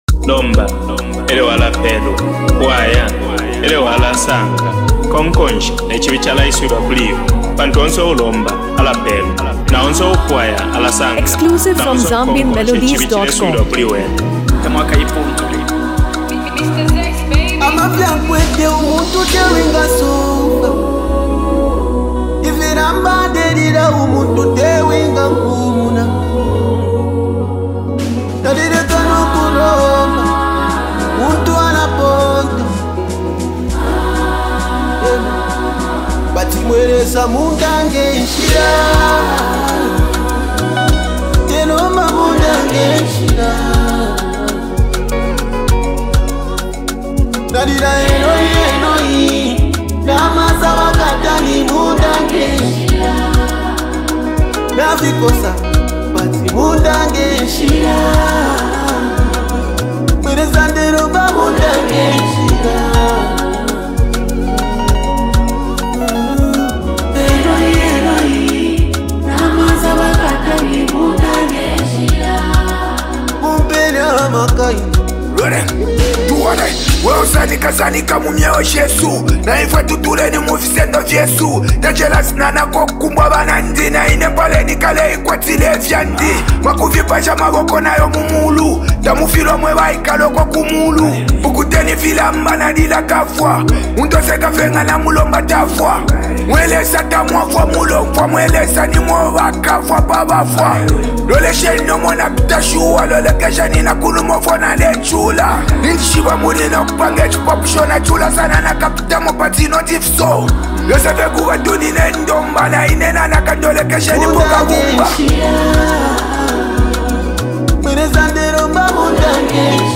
Best Zambian Hip-Hop 2026
Inspirational Zambian Song
Genre: Afro-beats